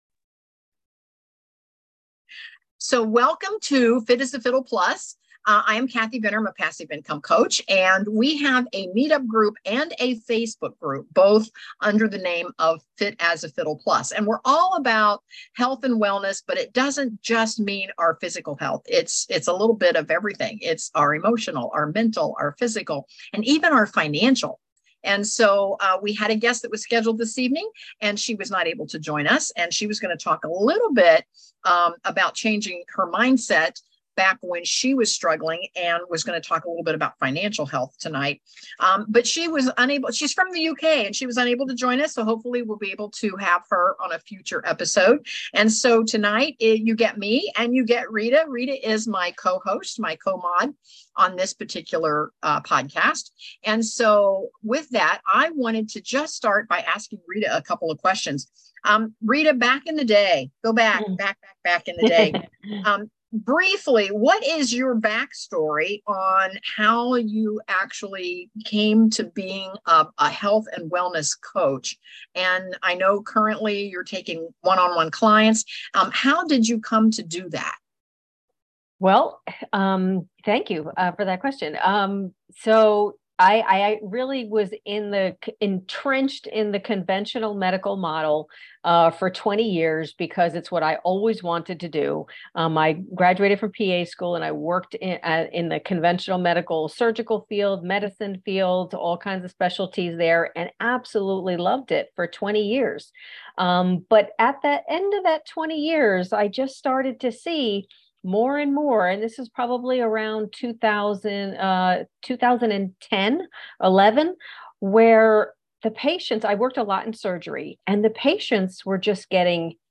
Fit as a Fiddle Plus | Interview